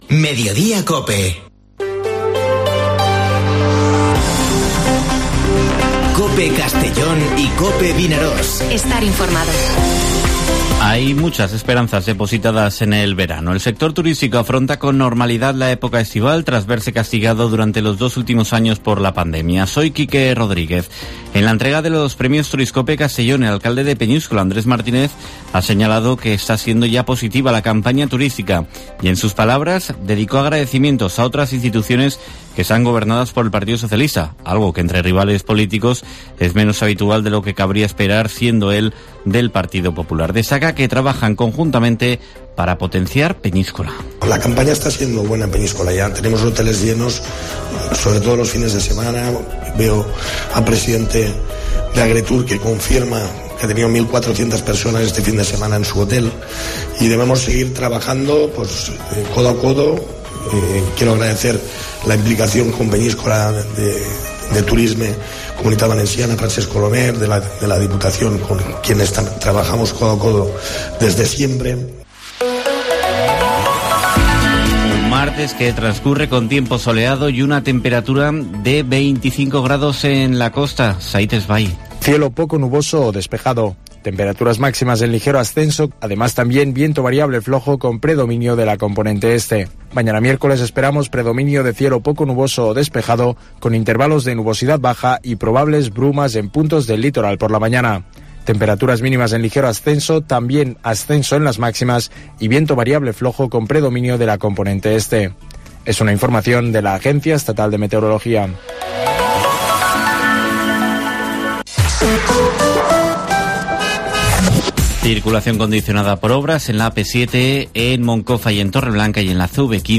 Informativo Mediodía COPE en la provincia de Castellón (31/05/2022)